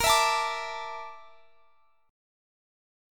BbM7b5 Chord
Listen to BbM7b5 strummed